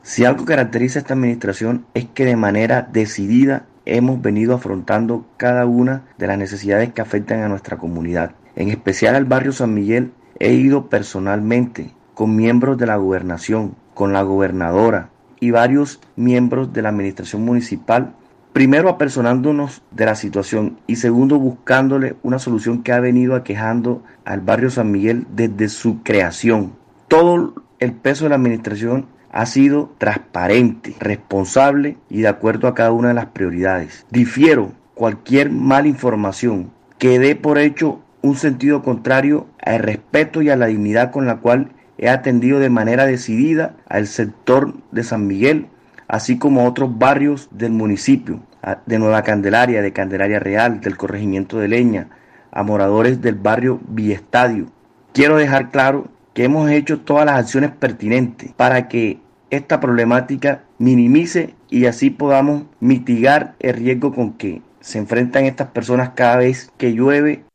VOZ-GREGORIO-BRITO-ALCALDE-CANDELARIA.mp3